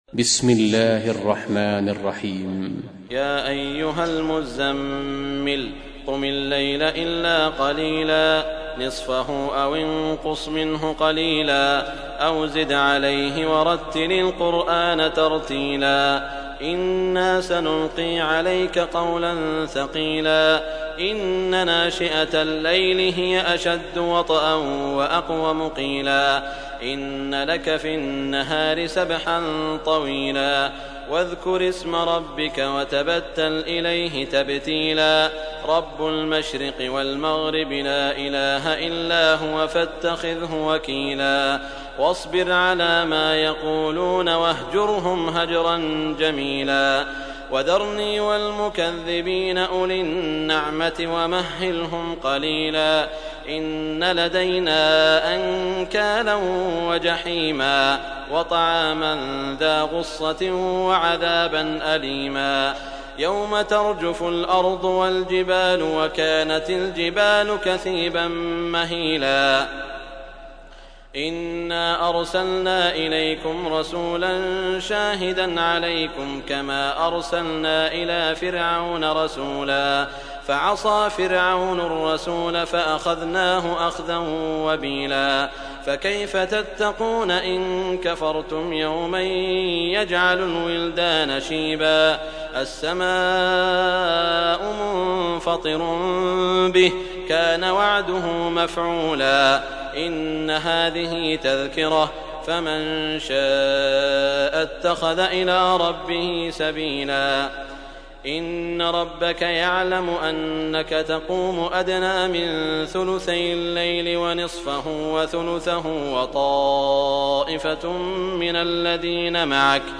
سورة المزمل | القارئ سعود الشريم